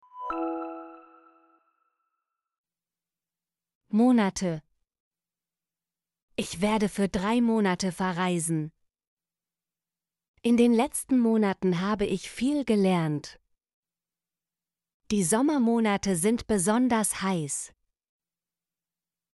monate - Example Sentences & Pronunciation, German Frequency List